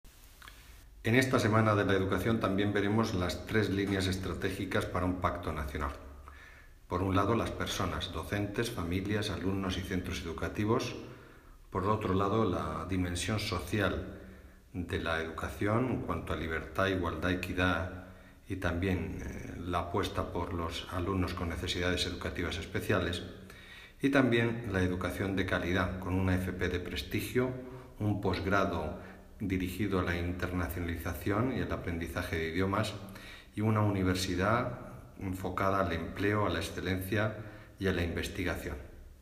Declaraciones del secretario de Estado de Educación, Formación Profesional y Universidades, Marcial Marín, tras su visita al Salón Internacional del Estudiante y de la Oferta Educativa, AULA 2016.